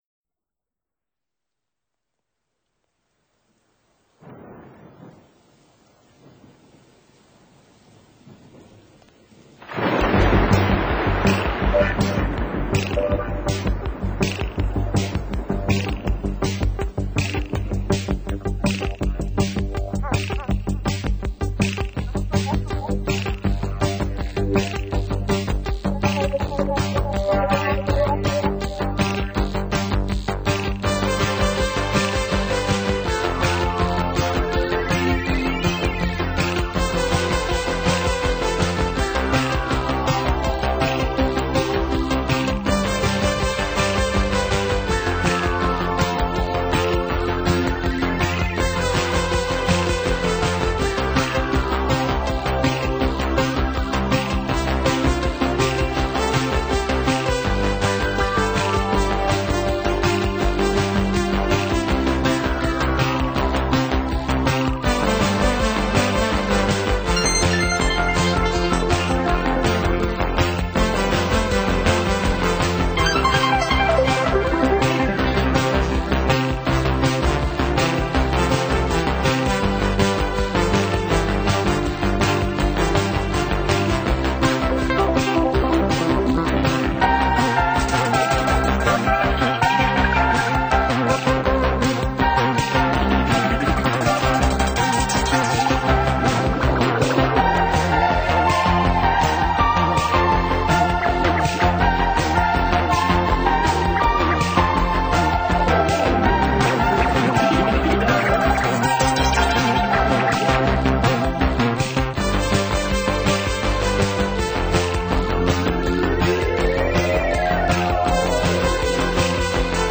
在戴上耳机的一瞬间你就 陷入了完全的音乐氛围中，真正的360度环绕声！
虽然分成8段，其实是连贯演奏的。